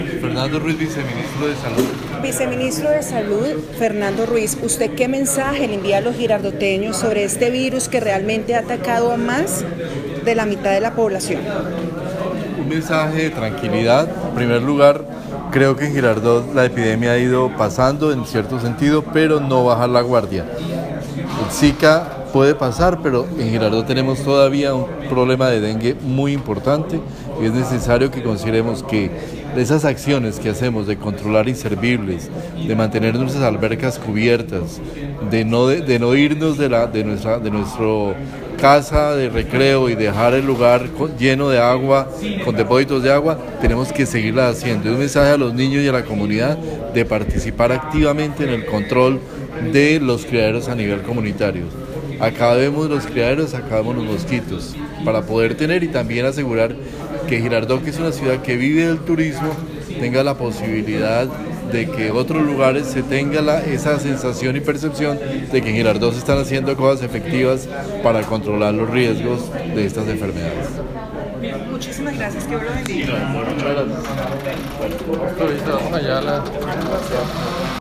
V etapa de vuelta a Colombia contra Zika arrancó hoy en Girardot
Audio: Mensaje de ViceSalud a Girardoteños para control del zika